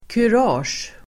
Uttal: [kur'a:sj]